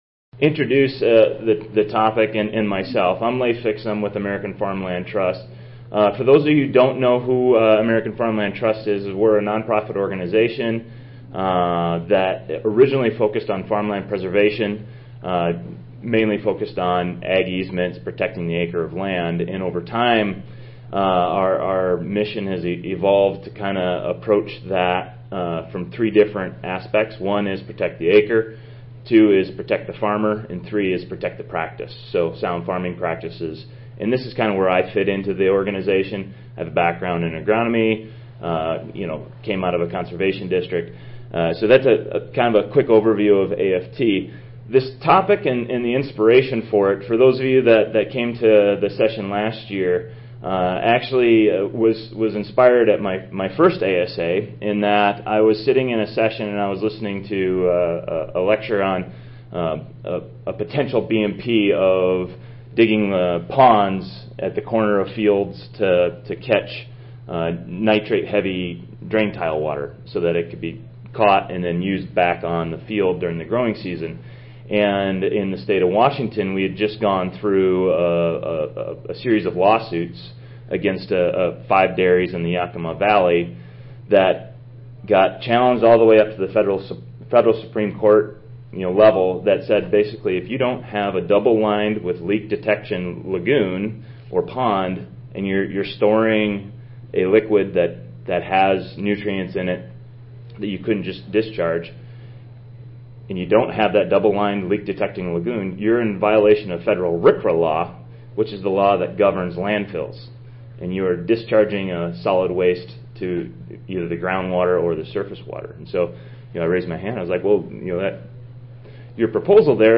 Nutrient Management: Science, Laws and Regulations Oral Session
Tampa Convention Center, Room 8